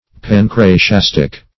Search Result for " pancratiastic" : The Collaborative International Dictionary of English v.0.48: Pancratiastic \Pan*cra`ti*as"tic\, a. Of or pertaining to the pancratium.